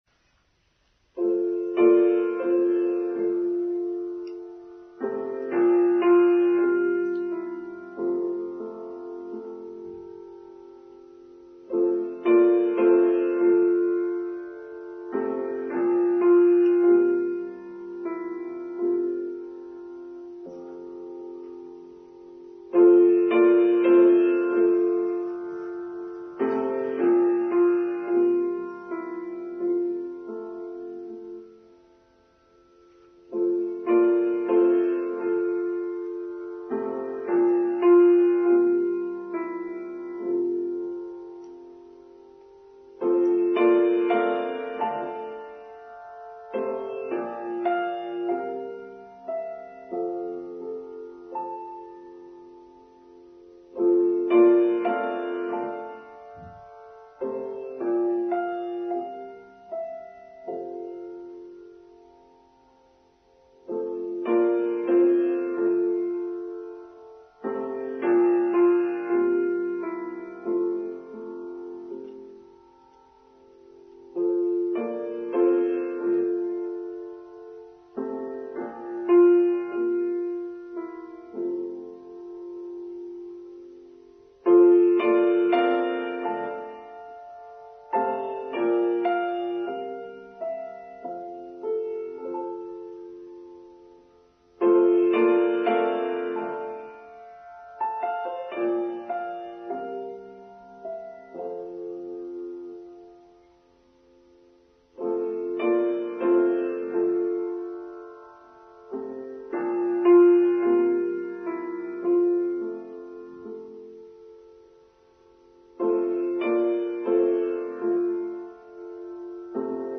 Kindness: Online Service for Sunday 15th January 2023